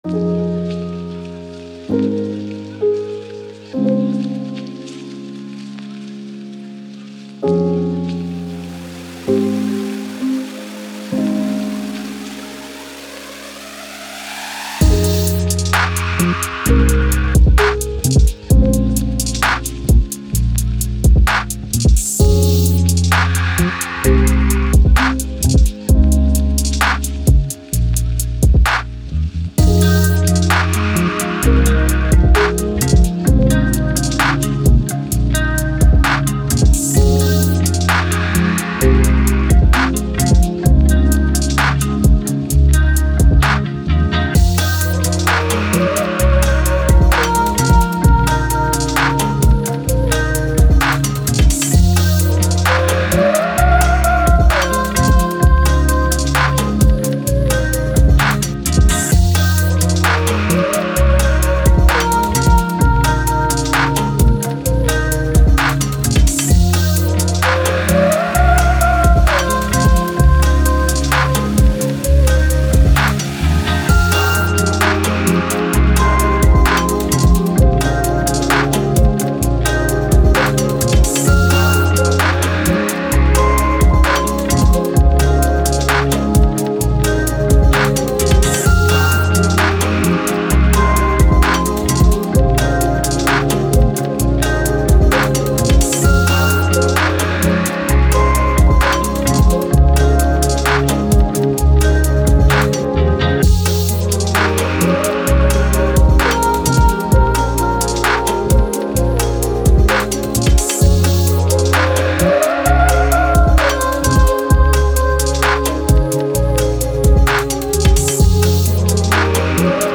Electronic, Thoughtful, Ambient, Downtempo